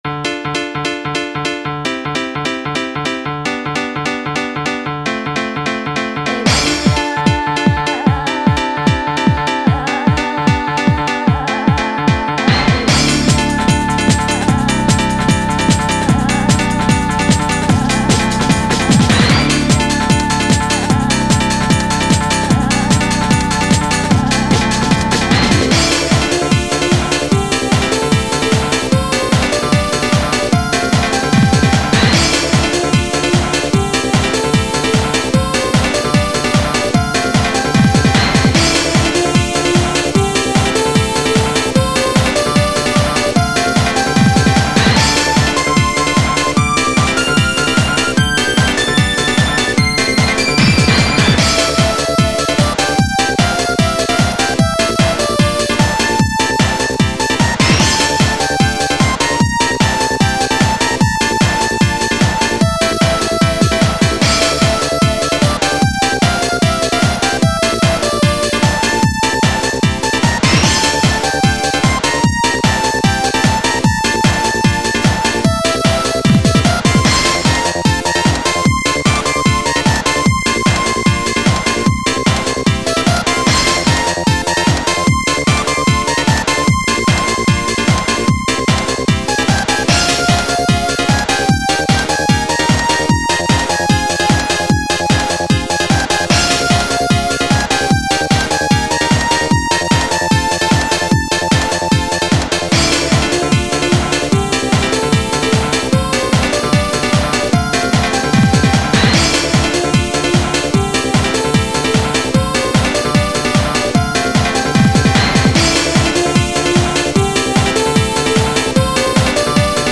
PMD という PC-98 用の FM 音源ドライバで作成してあります。PPZ8 も使用しています。